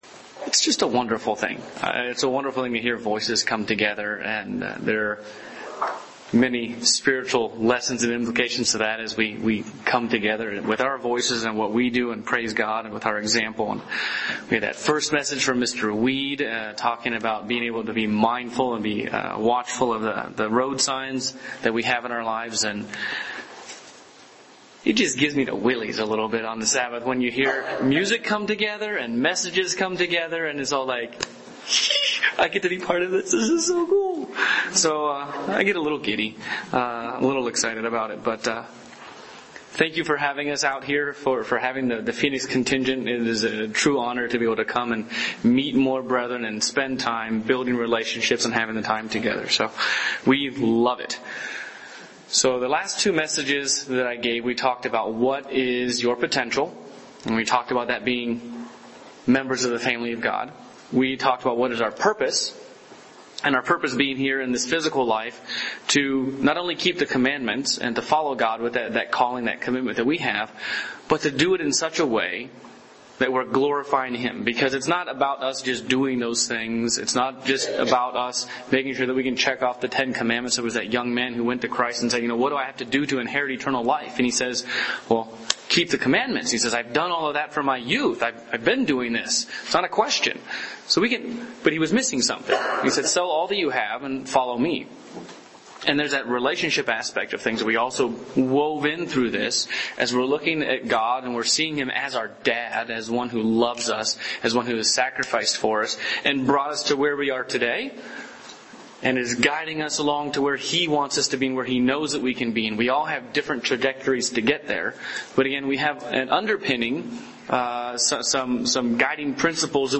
Knowing what you can be and what is expected of you does not make meeting these goals any easier. In this sermon, we'll delve into how we can truly fulfill the walk that God has set before each of us.